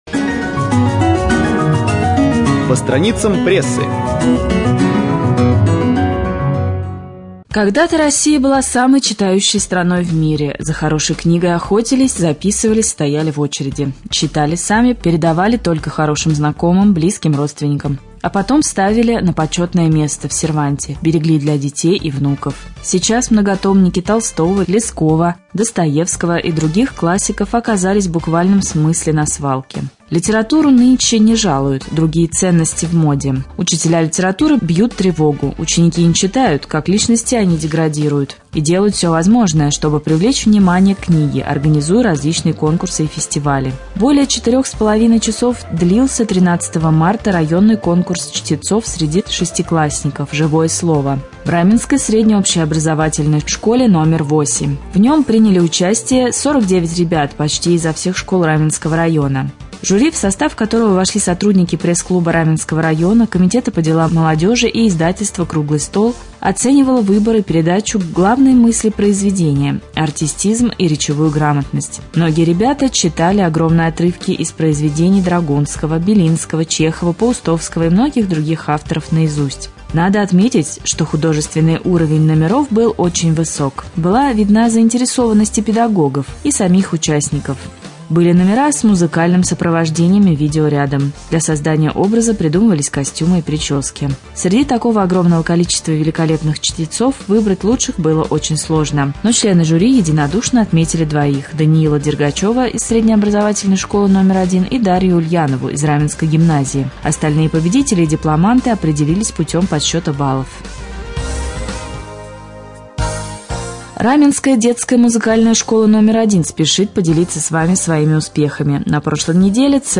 20.03.2014г. в эфире раменского радио - РамМедиа - Раменский муниципальный округ - Раменское